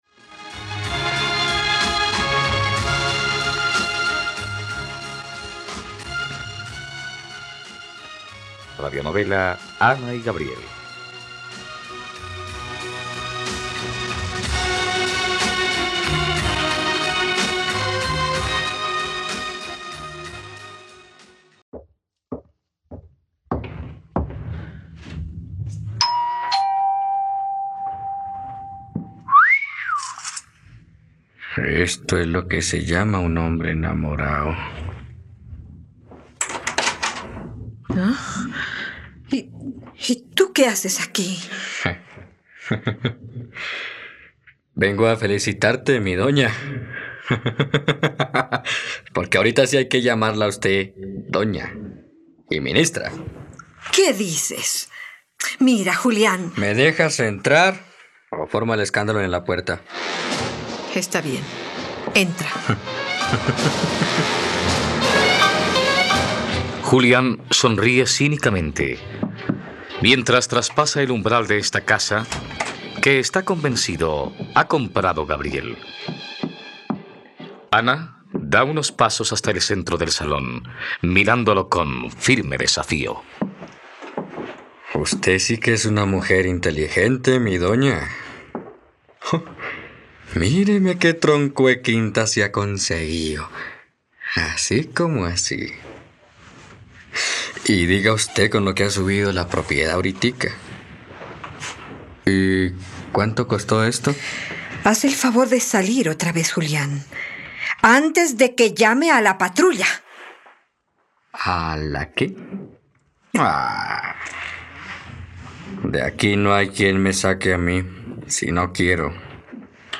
..Radionovela. Escucha ahora el capítulo 77 de la historia de amor de Ana y Gabriel en la plataforma de streaming de los colombianos: RTVCPlay.